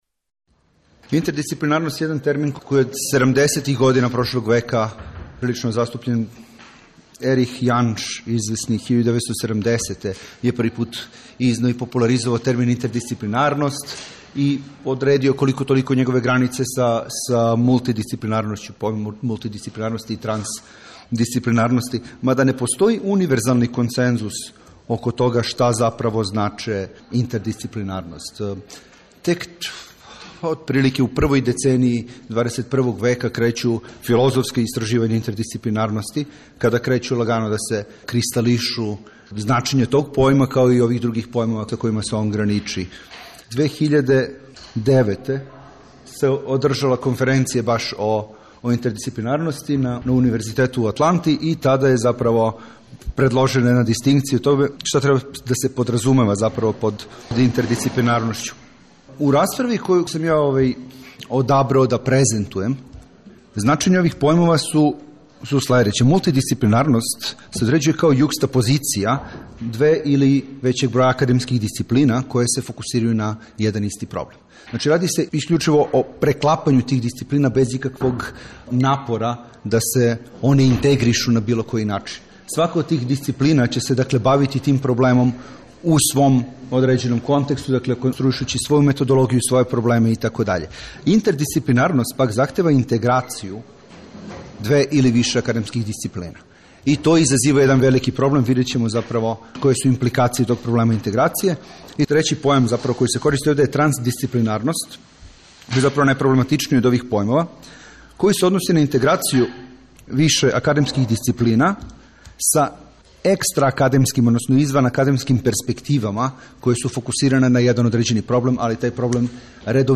У циклусу НАУКА И САВРЕМЕНИ УНИВЕРЗИТЕТ четвртком ћемо емитовати снимке са истоименог научног скупа, који је одржан 11. и 12. новембра на Универзитету у Нишу.
Научни скупови